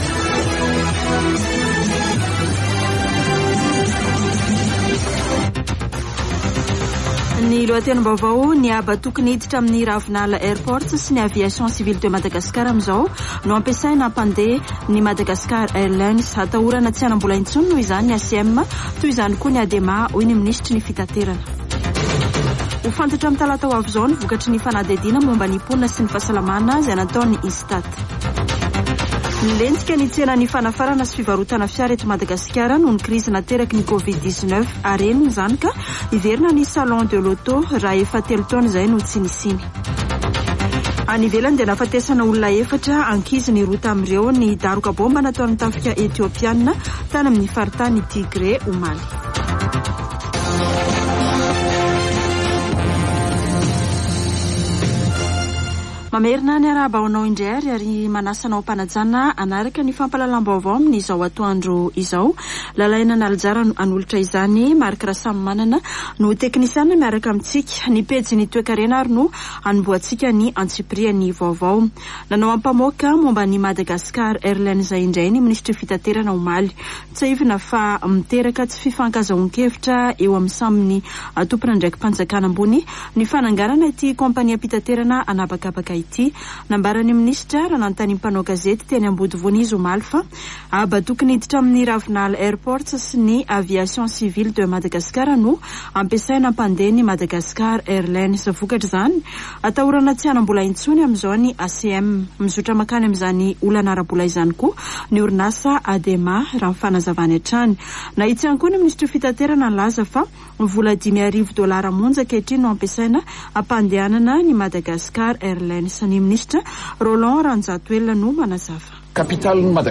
[Vaovao antoandro] Sabotsy 27 aogositra 2022